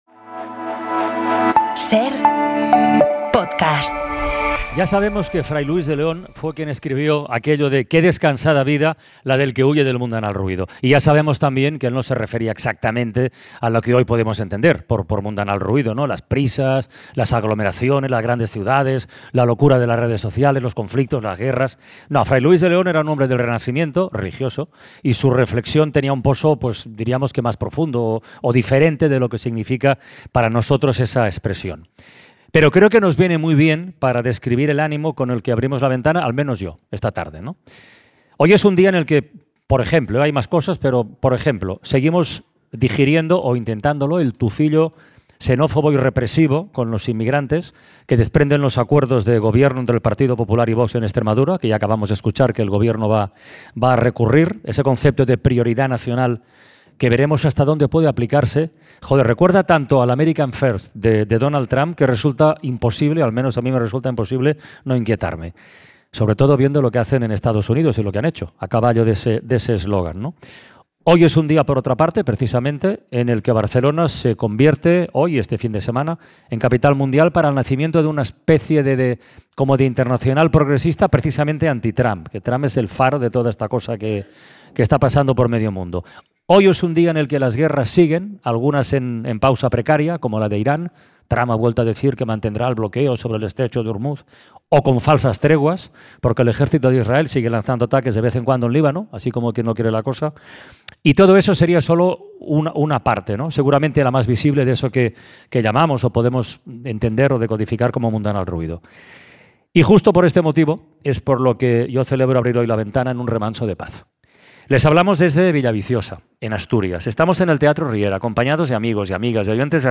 Durante este programa especial desde la Villaviciosa, Asturias, hablamos con Adrián Barbón, presidente del Principado de Asturias.